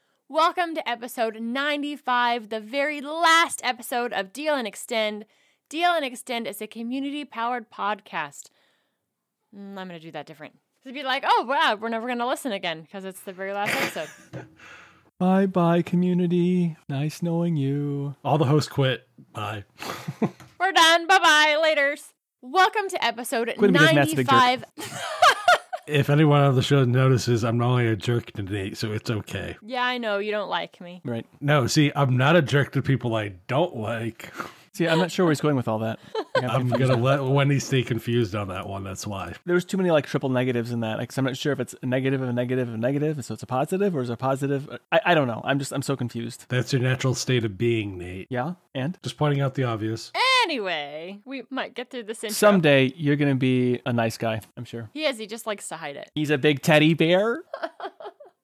Here is an outtake from episode 95 of the The last show to be done as Xtend.